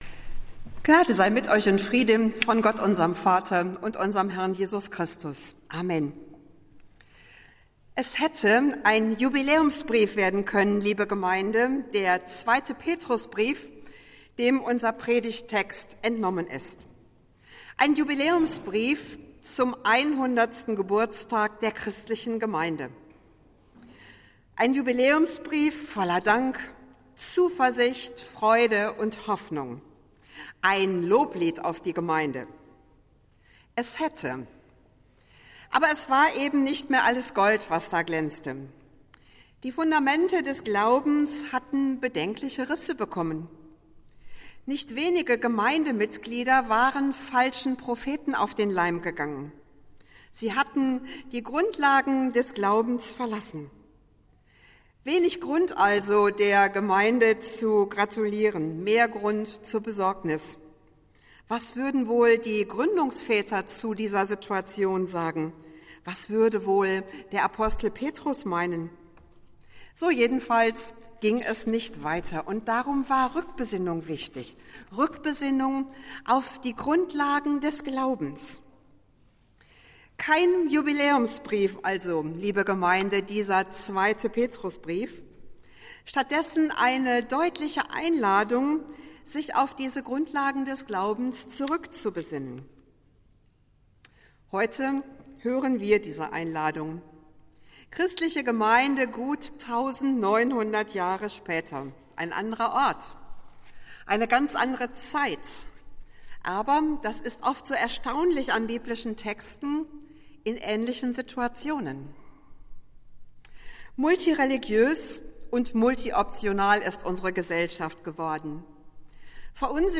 Predigt des Gottesdienstes aus der Zionskirche vom Sonntag, 30.01.2022